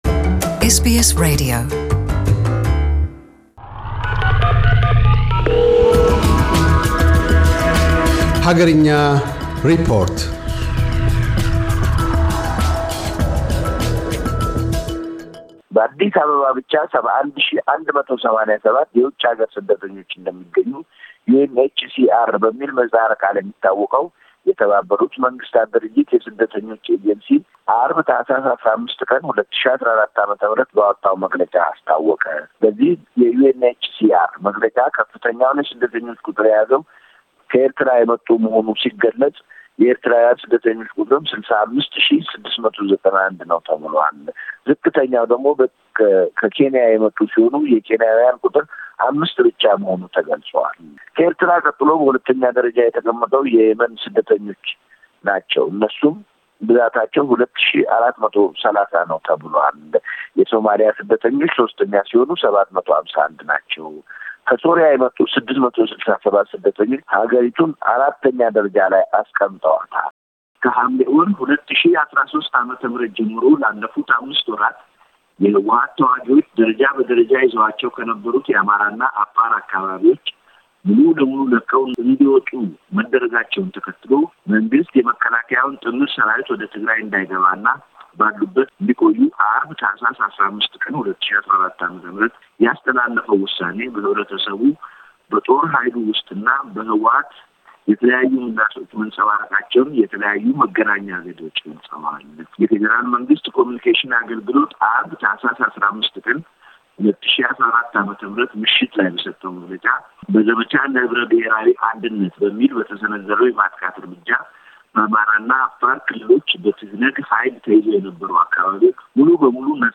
*** አገርኛ ሪፖርት